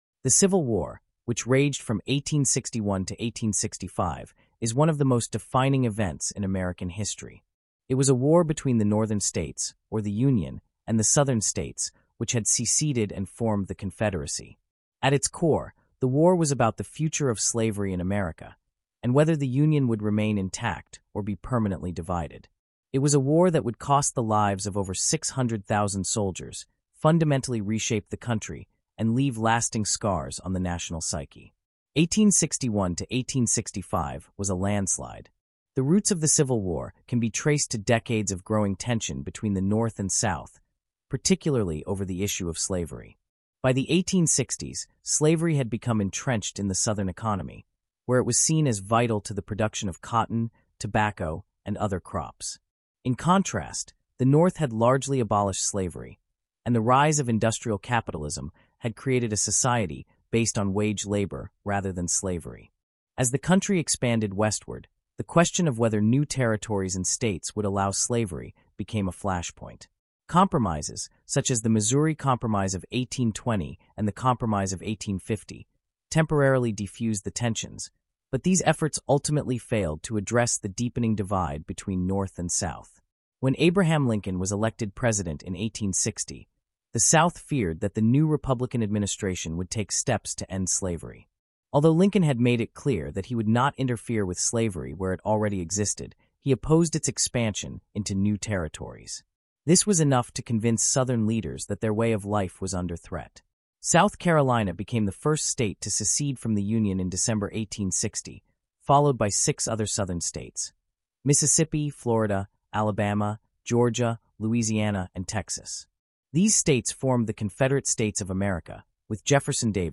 Disclosure: This podcast includes content generated using an AI voice model.